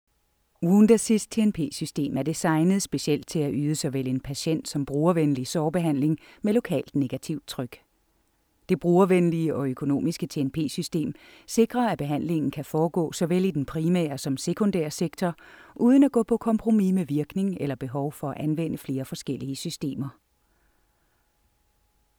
Profi Sprecherin dänisch. Natural speech. Warm, Persuasive, Mature, Narration, commercials, telephone systems
Sprechprobe: eLearning (Muttersprache):
Highly experienced professional female danish voice over artist. Natural speech. Warm, Persuasive, Mature, Narration, commercials, telephone systems